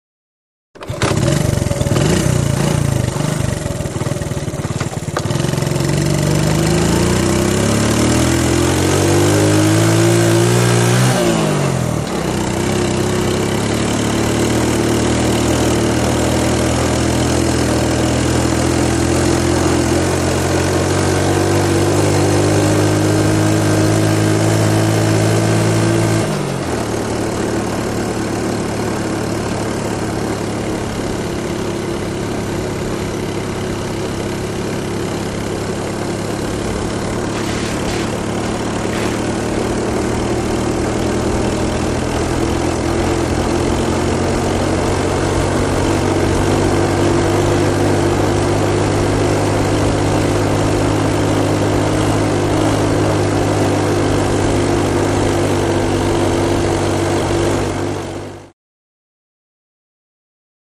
Motorcycle; Constant; Two Stroke Trike Start Up And Away To Constant Riding On.